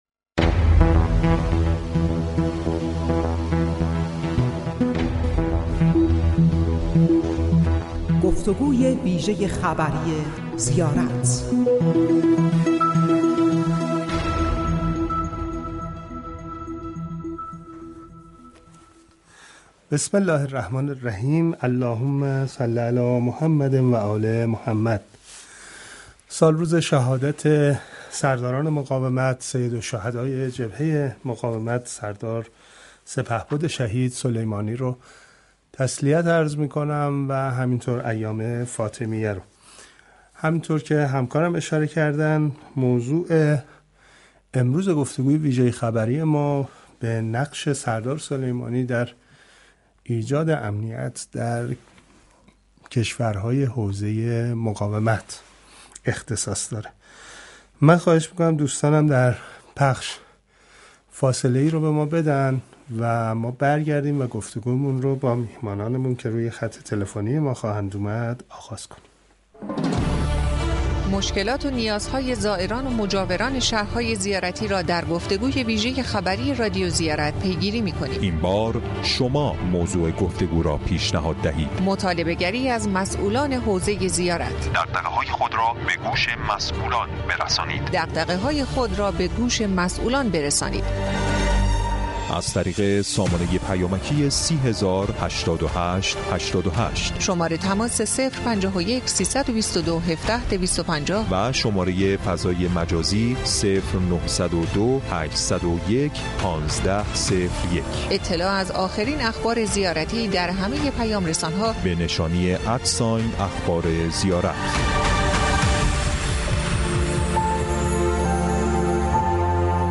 گفتگوی ویژه خبری رادیو زیارت